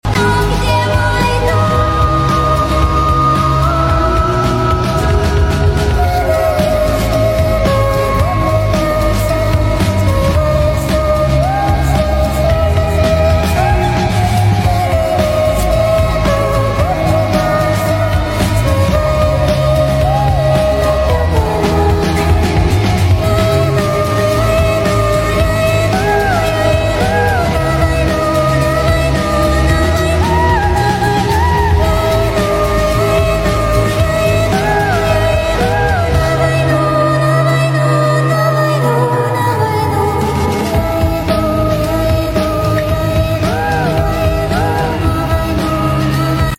Sped up and reverb added.